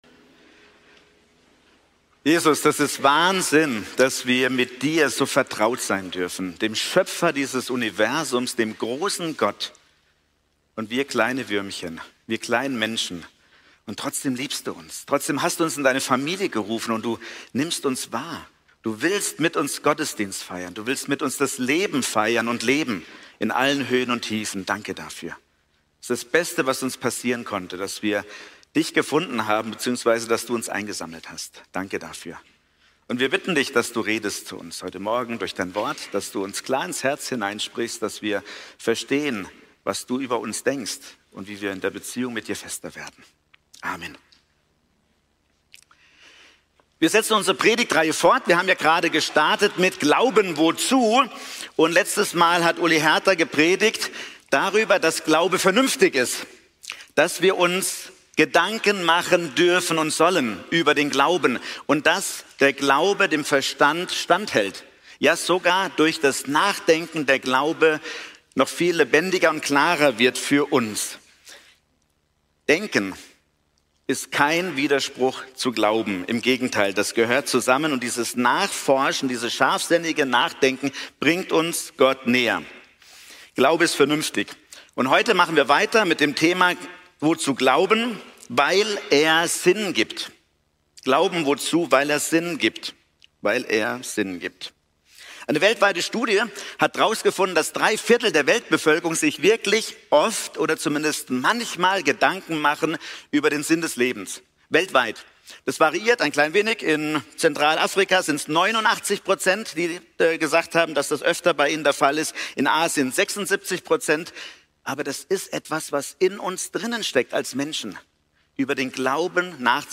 Typ: Predigt Glauben ist vernünftig!